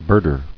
[bird·er]